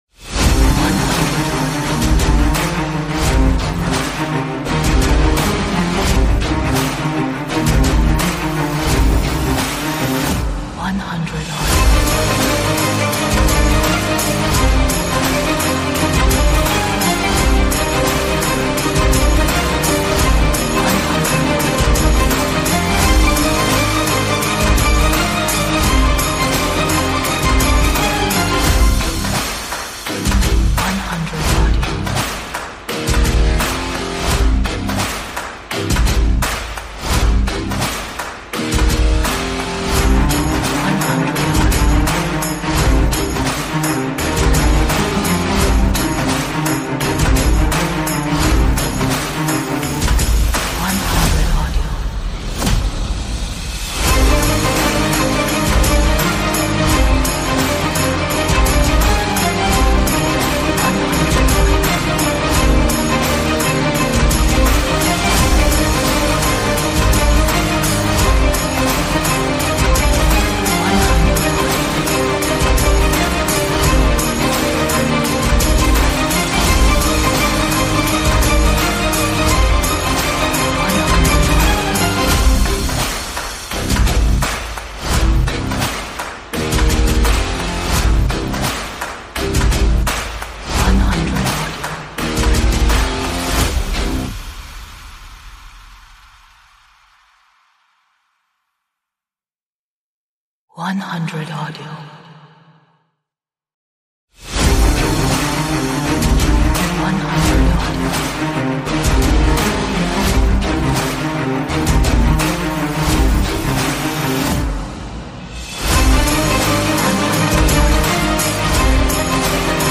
is powerful track with aggressive guitar riffs.